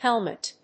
/ˈhɛlmʌt(米国英語), ˈhelmʌt(英国英語)/